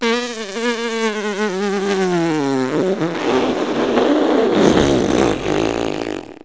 longblow.wav